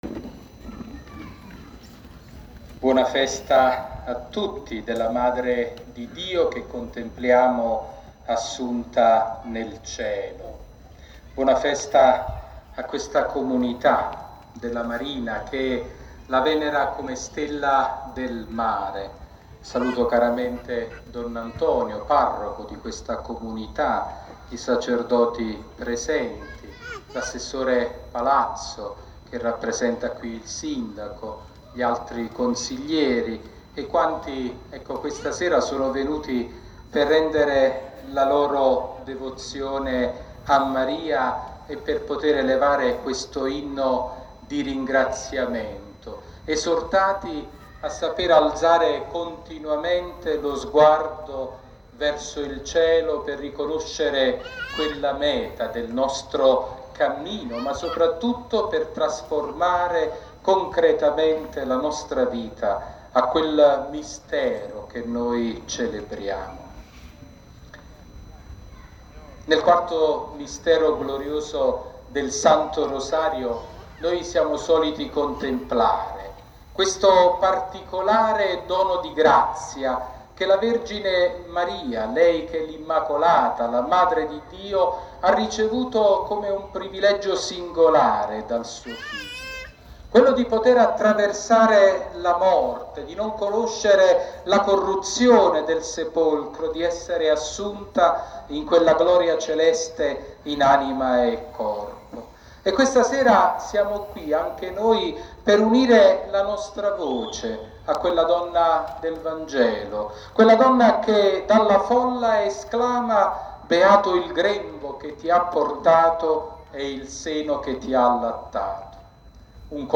Omelia di Mons. Sabino Iannuzzi durante la Santa Messa in onore di S. Maria Stella Maris
L'omelia del Vescovo Sabino durante la Santa Messa per la Festa di S. Maria Stella Maris presso l'omonima parrocchia di Castellaneta Marina.
Omelia-Vescovo-Sabino-stella-maris-14-8-24.mp3